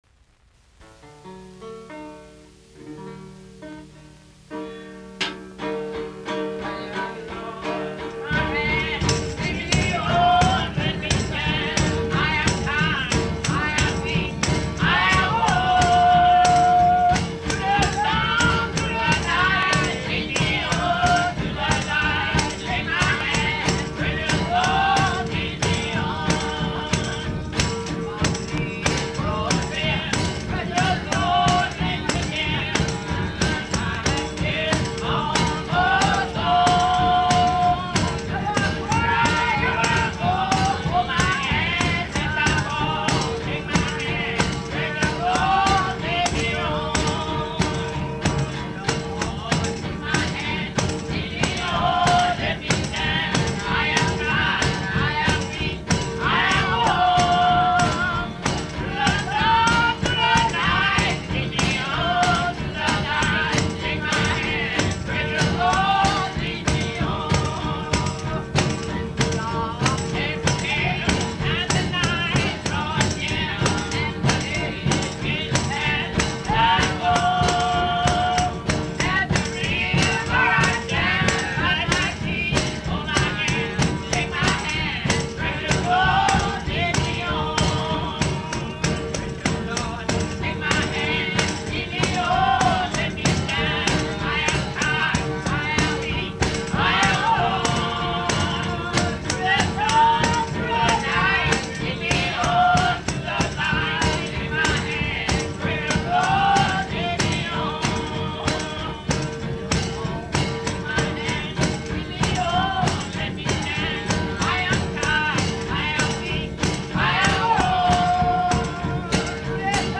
Music from the south : field recordings taken in Alabama, Lousiana and Mississippi.